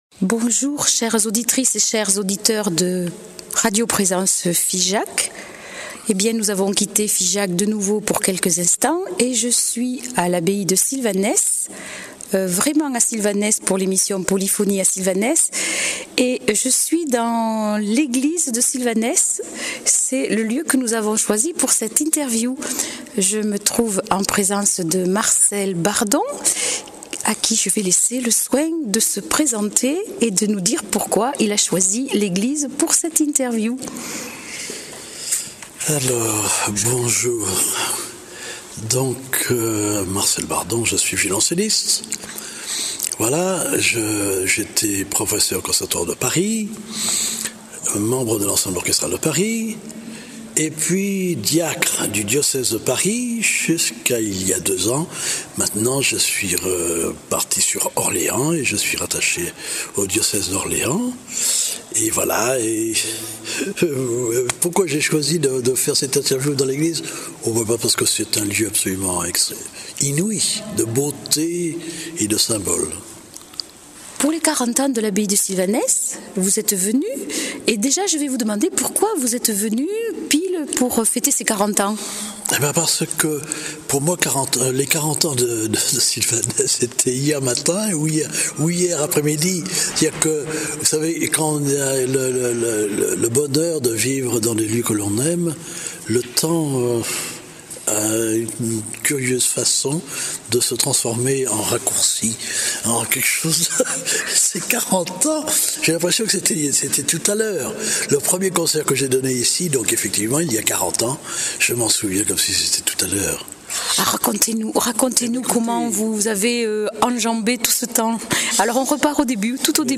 violoncelliste